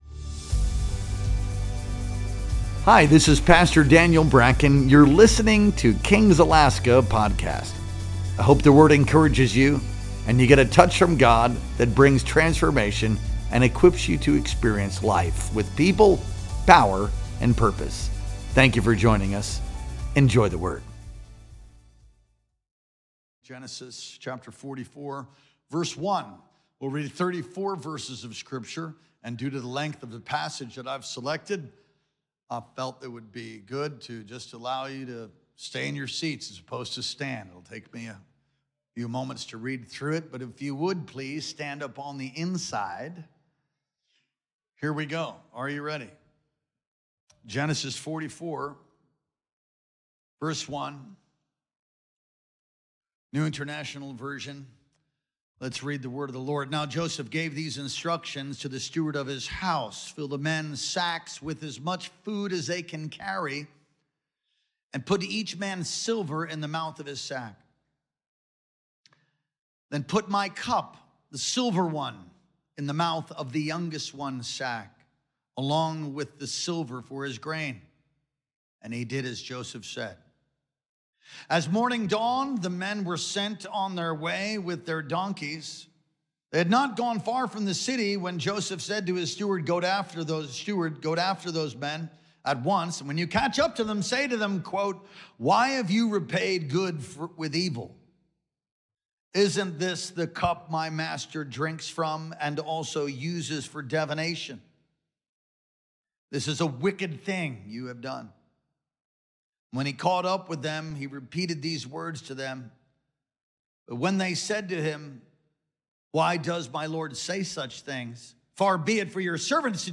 Our Sunday Worship Experience streamed live on June 15th, 2025.